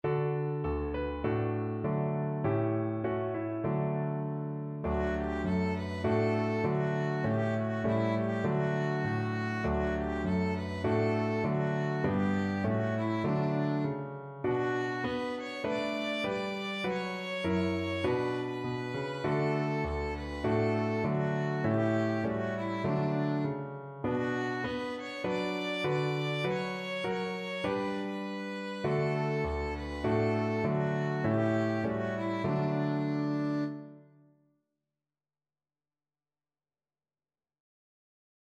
Violin
D major (Sounding Pitch) (View more D major Music for Violin )
Simply
4/4 (View more 4/4 Music)
D5-D6
Traditional (View more Traditional Violin Music)
Israeli